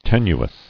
[ten·u·ous]